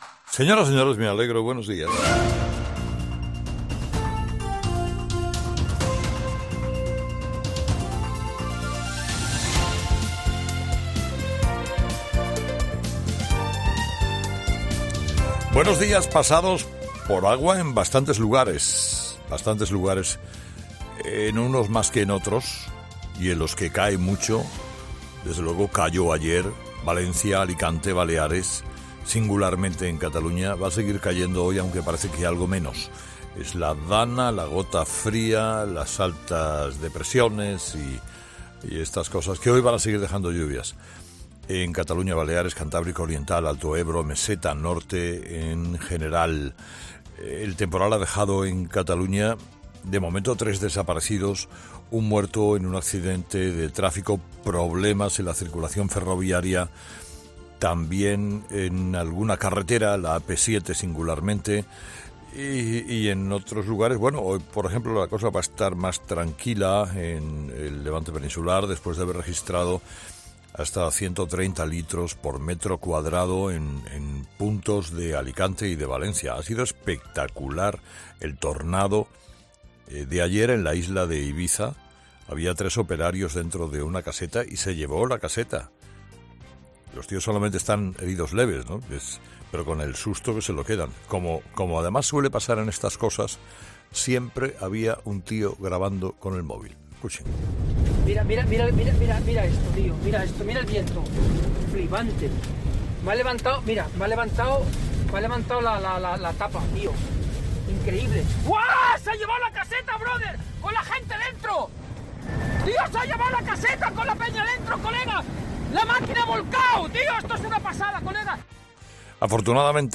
ESCUCHA AQUÍ EL EDITORIAL DE HERRERA Ha sido espectacular el tornado de ayer en la isla de Ibiza.